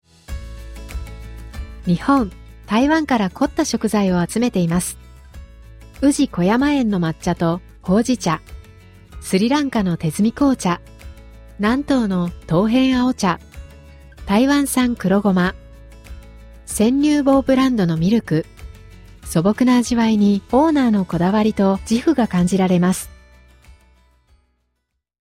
日本語音声ガイド